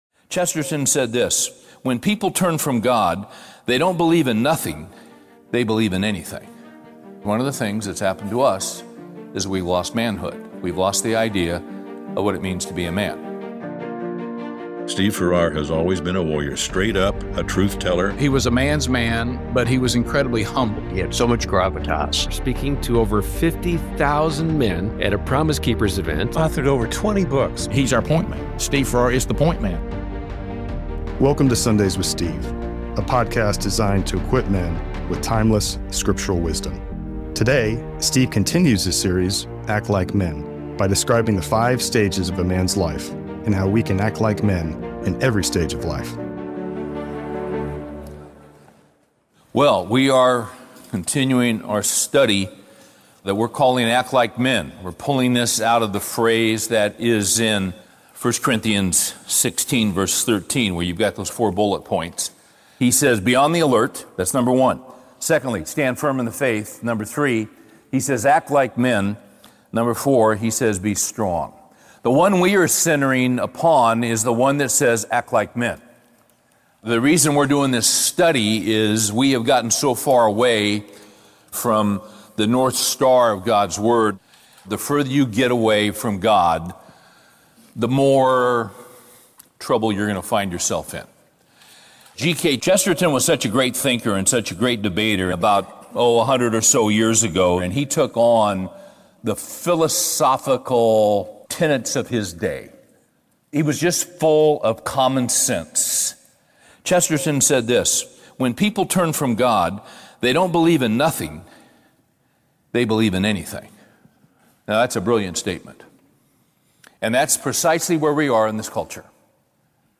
The length of a Sunday morning message, these weekly podcasts are designed for the man seeking biblical teaching that addresses pressing issues & feeds the soul.